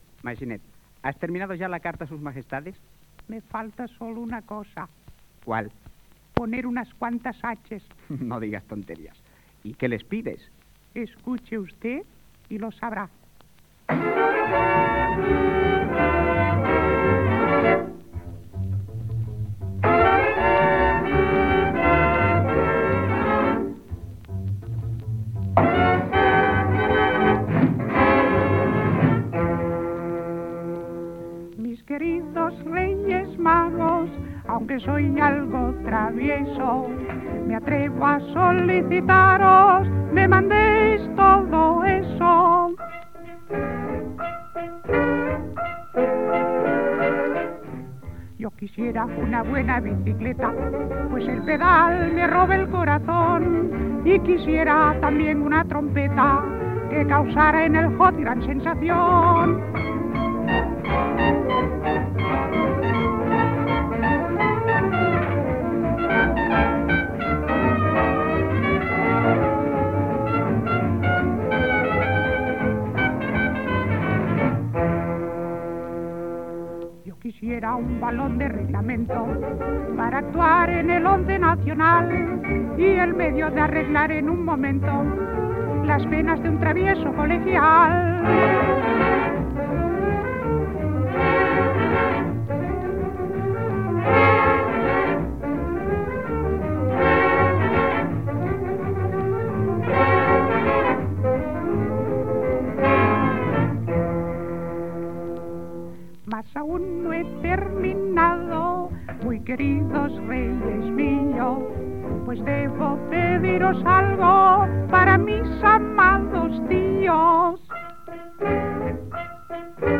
Infantil-juvenil
Extret del disc "Sueño de Maginet: fantasía infantil navideña", editat per Odeón.